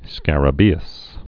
(skărə-bēəs)